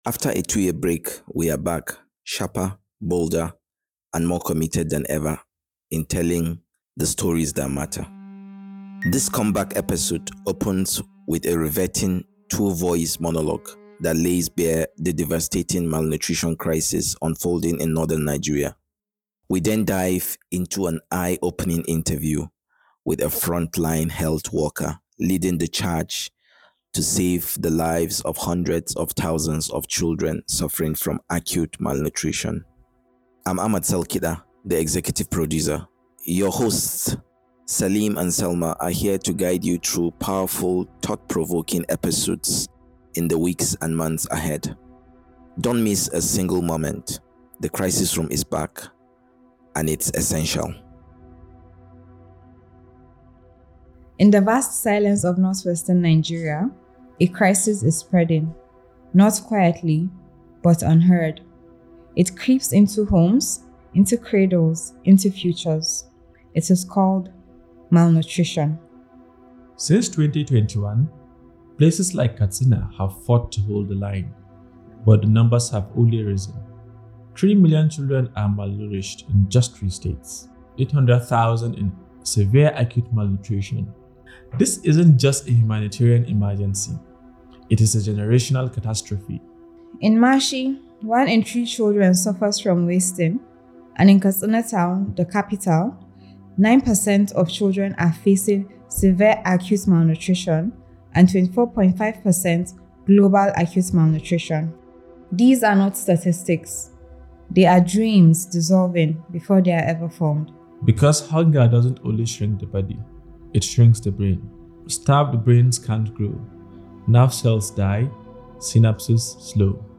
This week: a powerful conversation with MSF on Nigeria’s malnutrition crisis—where aid workers fight to save lives on the edge.